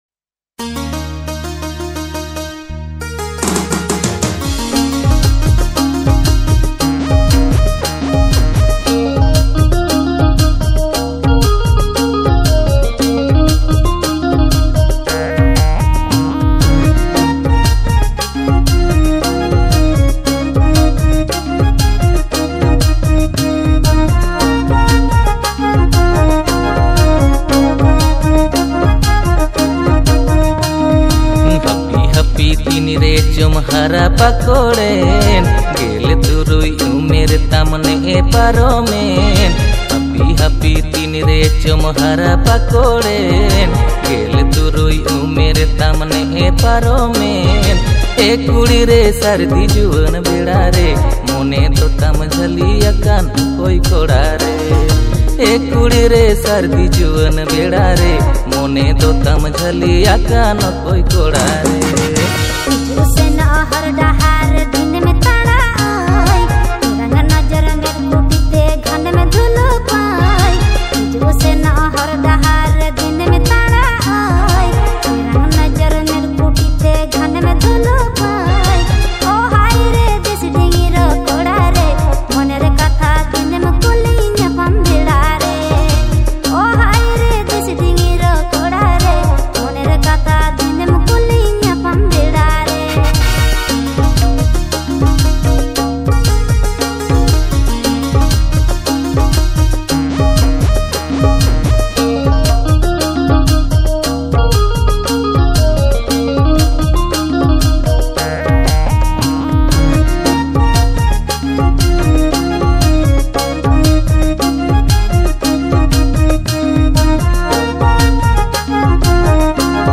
Santali song
• Male Artist